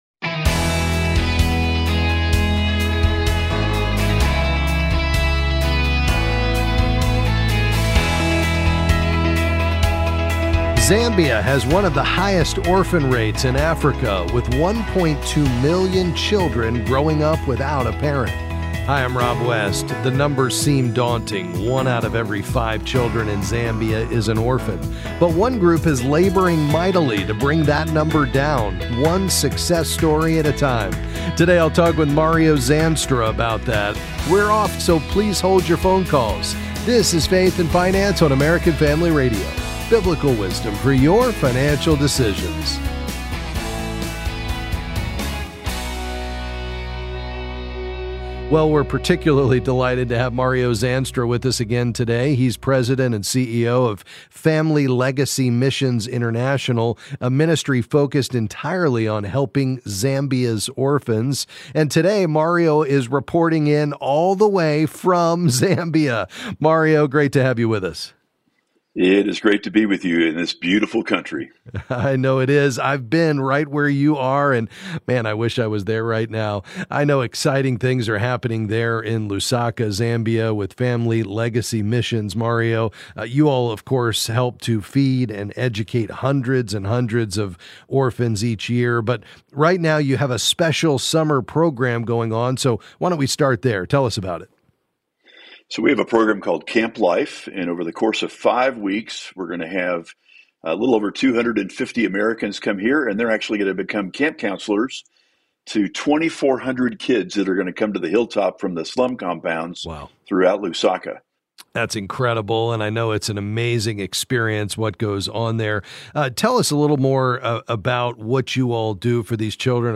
Then he’ll answer your questions on various financial topics.